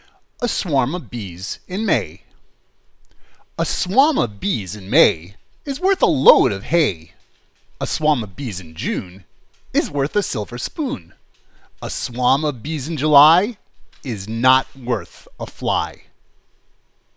A Swarm of Bees in May - Chansons enfantines anglaises - Angleterre - Mama Lisa's World en français: Comptines et chansons pour les enfants du monde entier